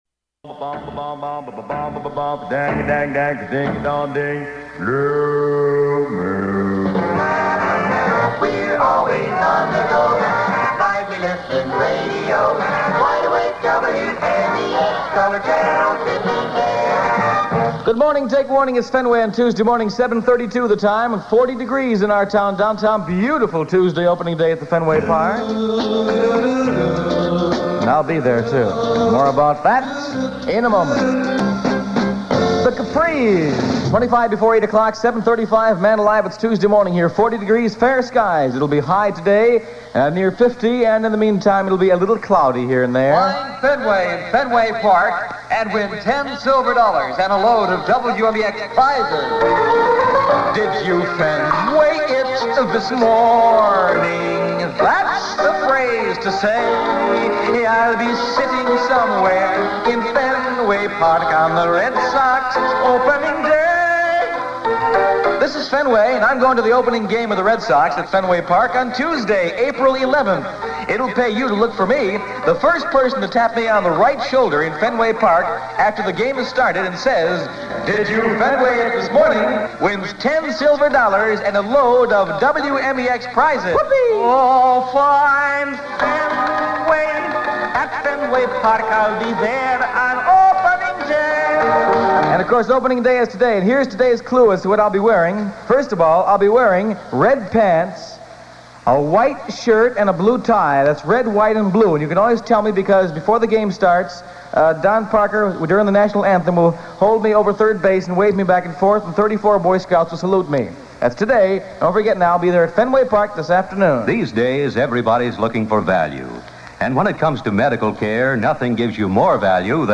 Aircheck of the Week